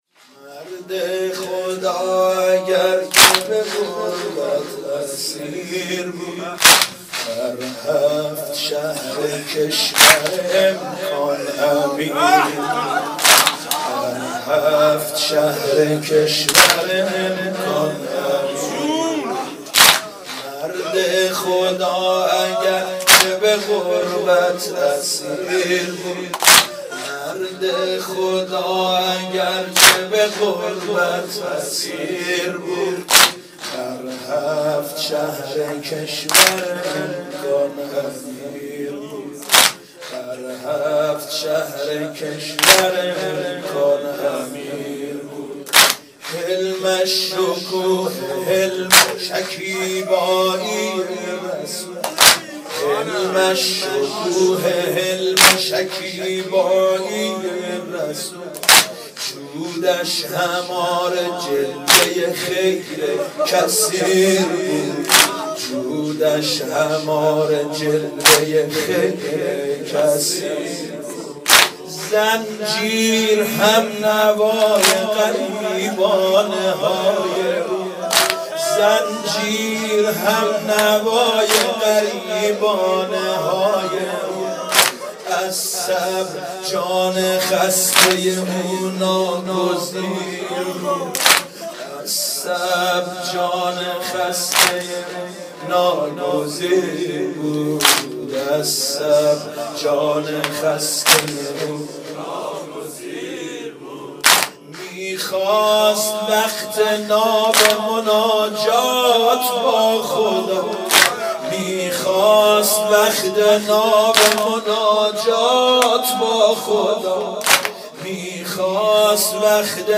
مناسبت : شهادت امام موسی‌کاظم علیه‌السلام